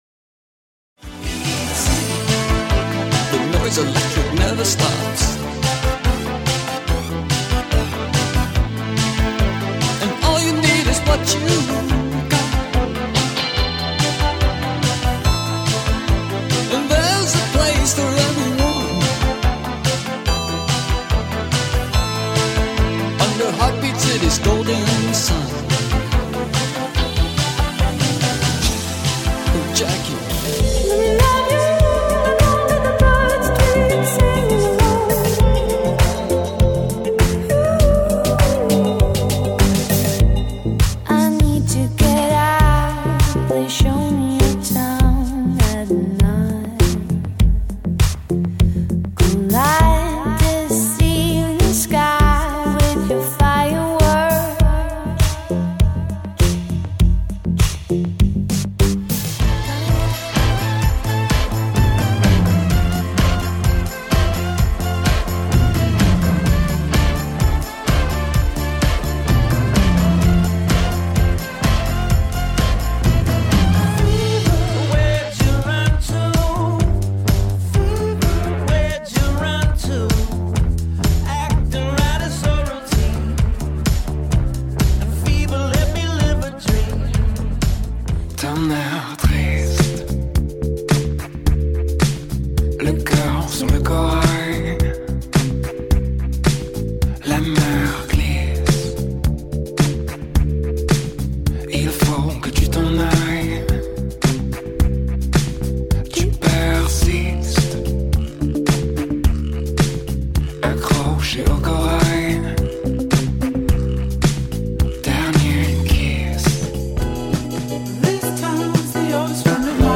High Tempo Eclectic Playlist for Coffee Shops